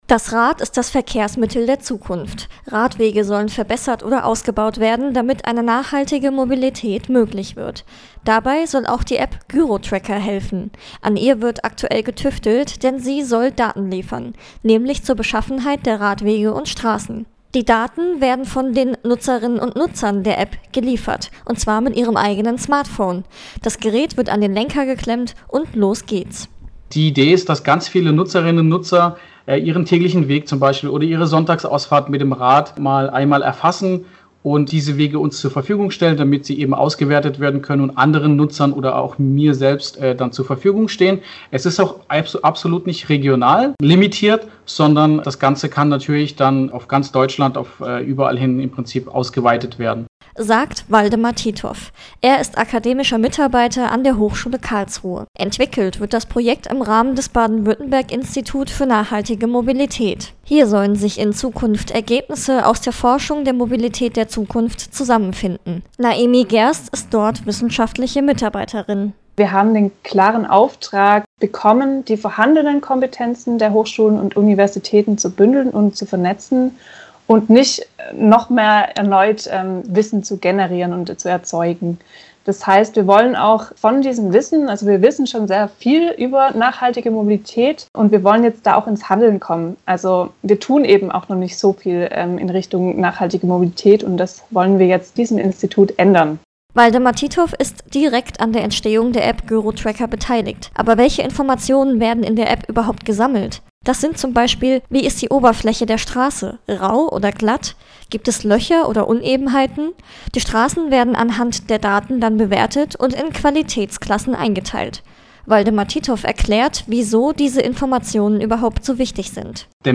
Den Beitrag kann man jetzt hier anhören: Radio-Beitrag über den GyroTracker.